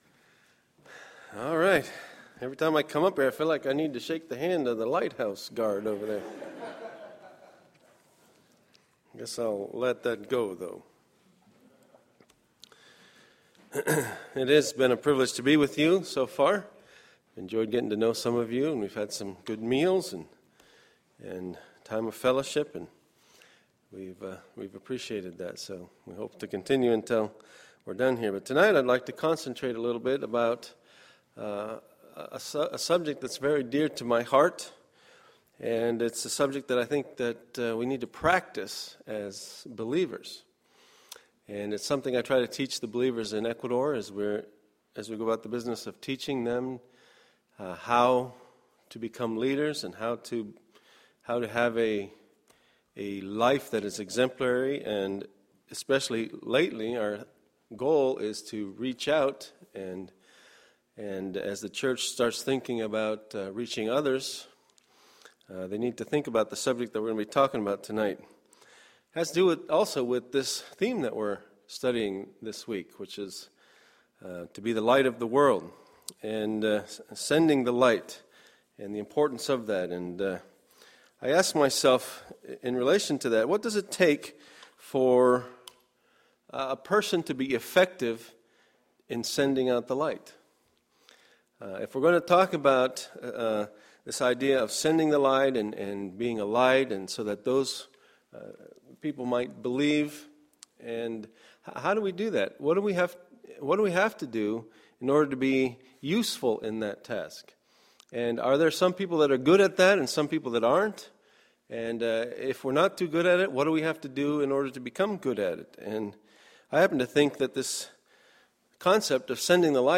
Monday, September 24, 2012 – Monday PM Session
Sermons
missions conference